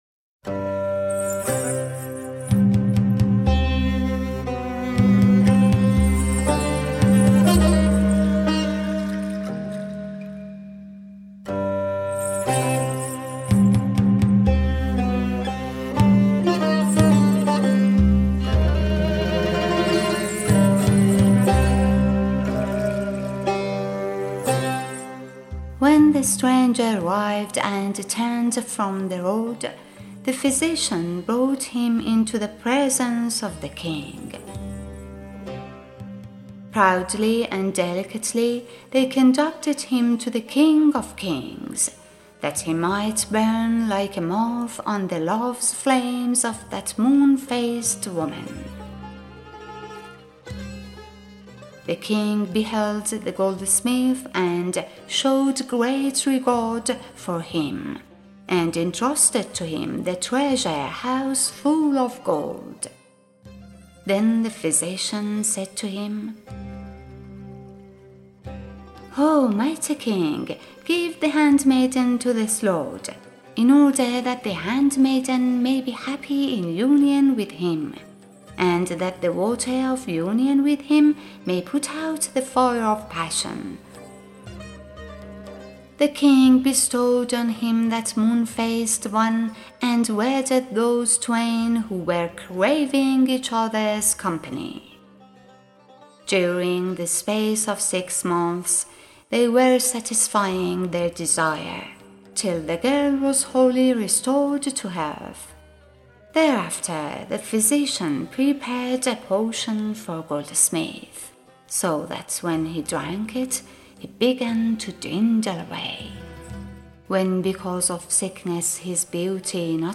Narrator and Producer: